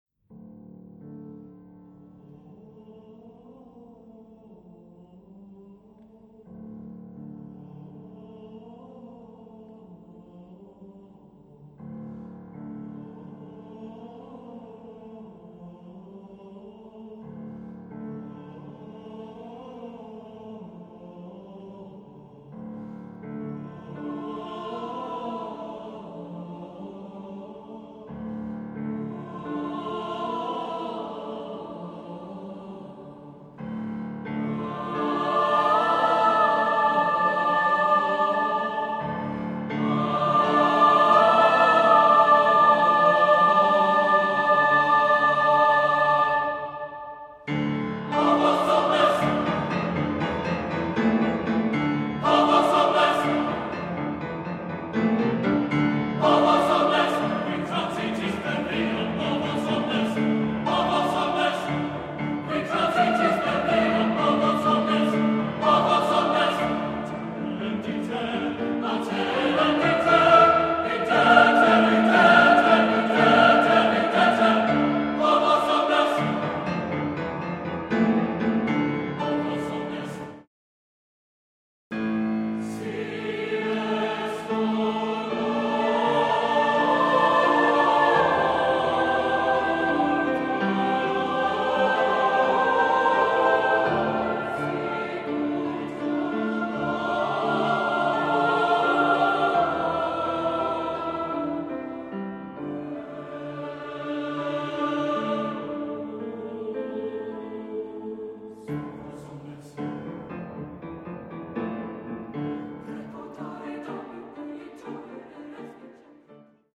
Genre-Style-Forme : contemporain ; Sacré ; Motet
Caractère de la pièce : sombre
Type de choeur : SATB  (4 voix mixtes )
Instrumentation : Piano  (1 partie(s) instrumentale(s))
Tonalité : ré mineur